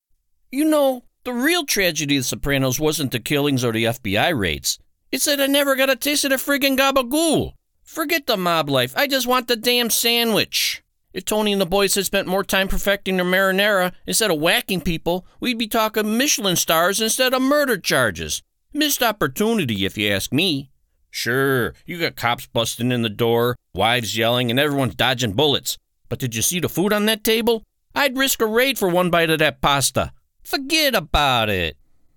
American Male Voice Over Artist
Soprano's Character spoof
I work out of a broadcast quality home studio with professional recording equipment and a quick turnaround time!